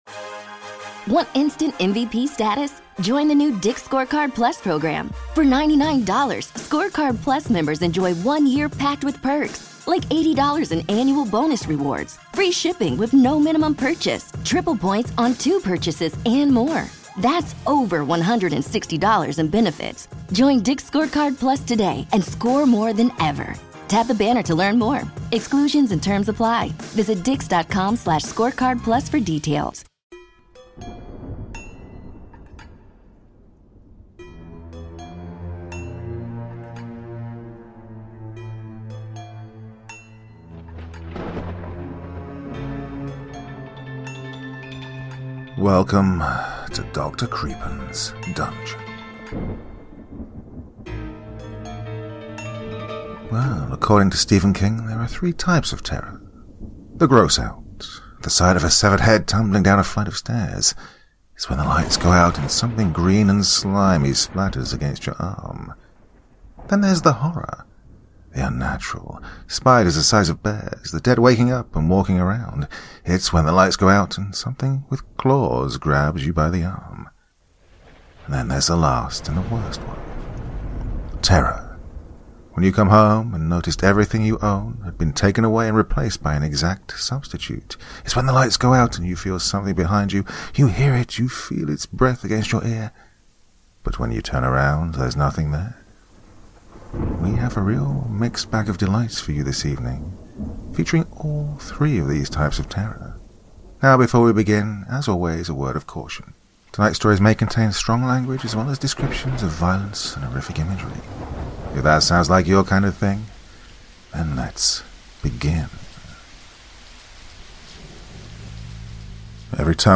Today’s fantastic opening story is ''Every time the ball drops, 2022 starts over again. And I’m the only one who remembers'', an original work by Channel X Horror, shared with me via my sub-reddit and read here with the author’s permission.